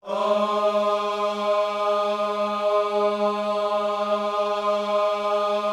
OHS G#3D  -R.wav